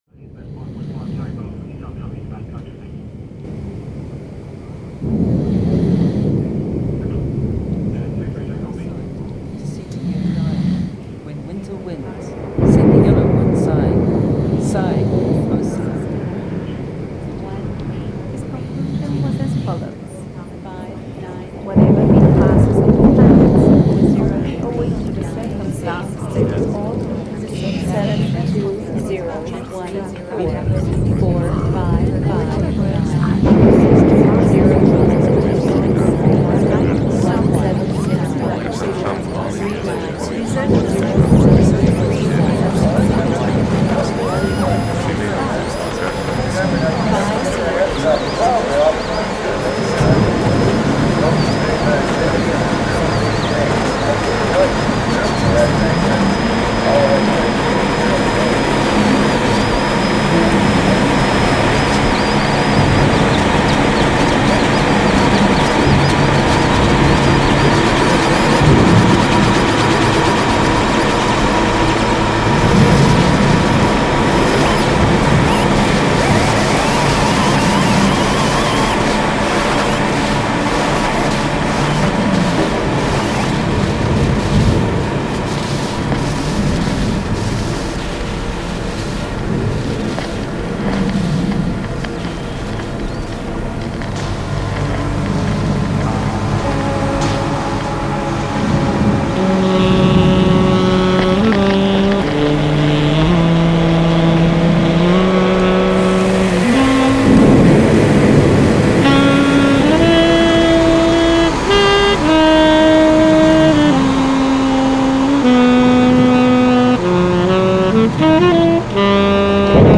Live Music, Saxophone